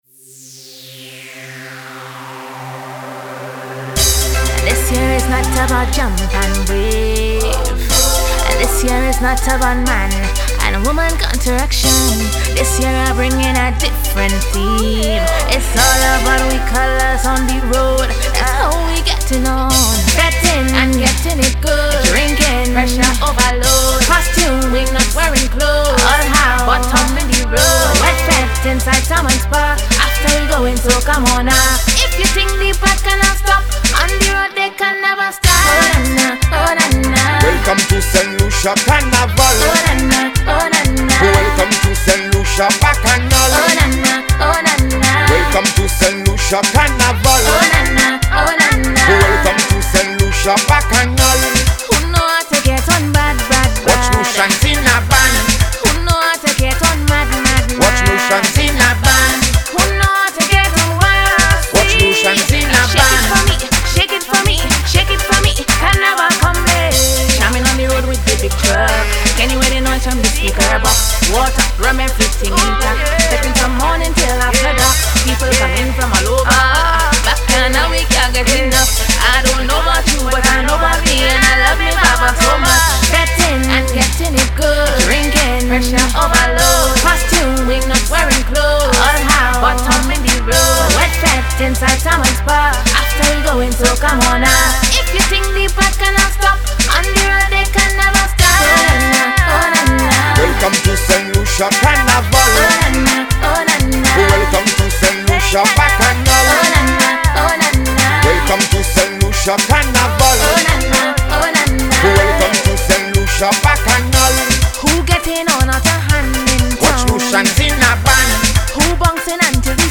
Saint Lucian music Media Info Title
Genre: Soca